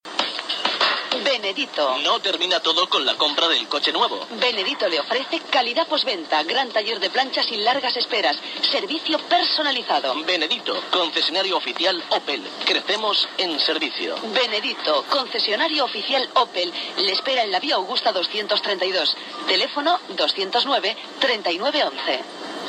Anuncio